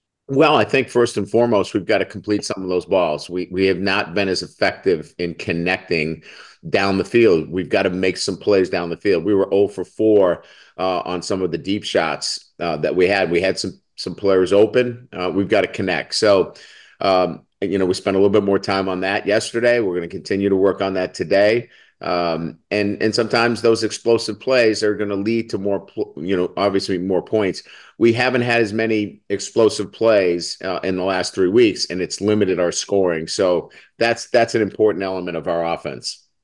In the game against Vanderbilt, Kelly explains how it’s important to regain explosiveness in the passing game:
Kelly-explosive-plays.wav